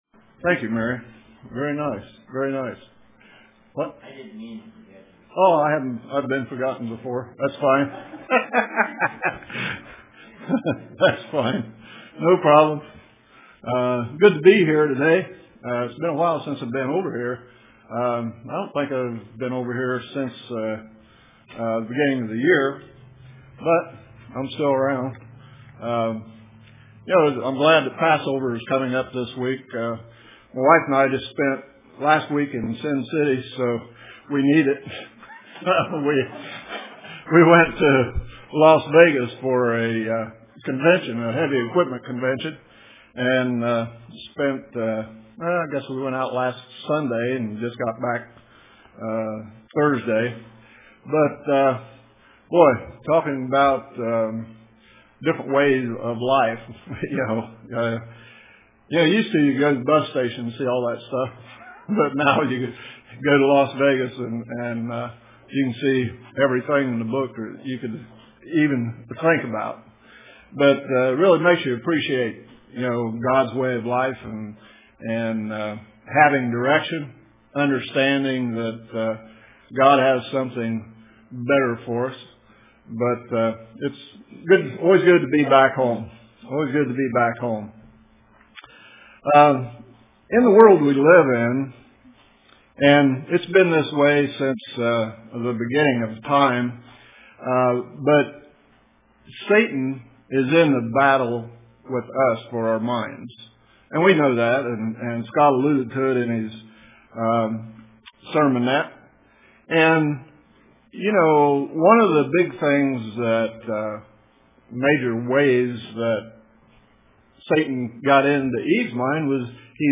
Print Satan is in a battle for our minds.Developing the Mind of Christ Through Unity UCG Sermon Studying the bible?